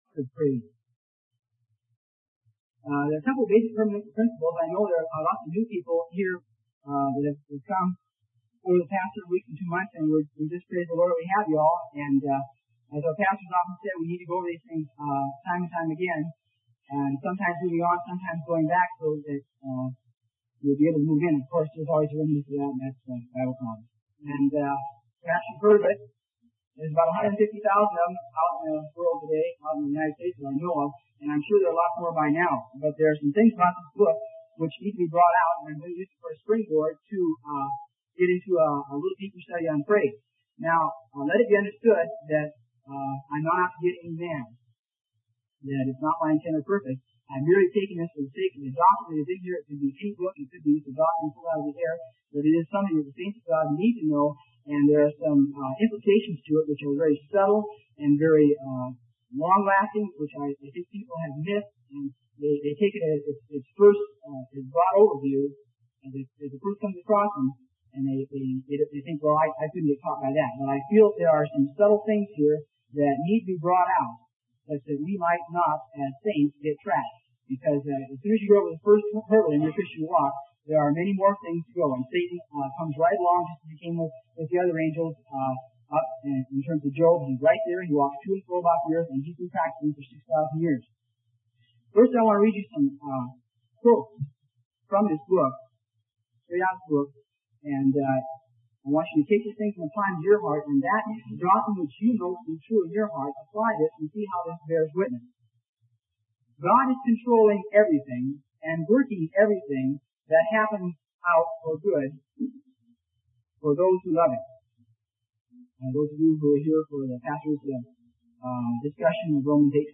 Sermon: The Heart of Praise - Freely Given Online Library